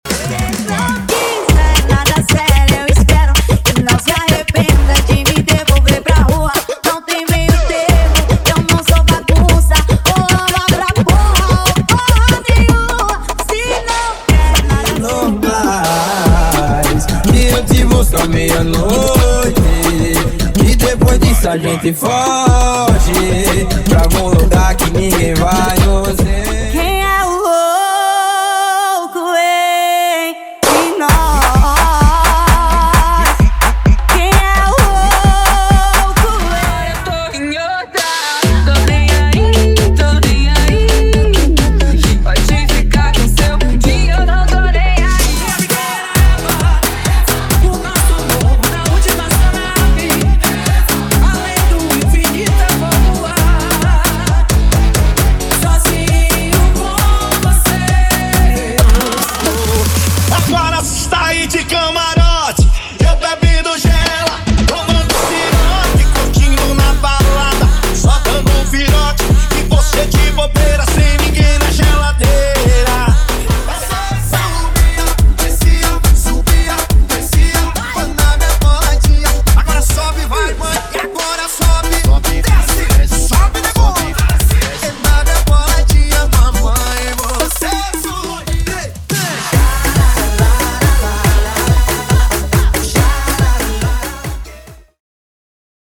FUNK LIGHT = 100 Músicas
Sem Vinhetas
Em Alta Qualidade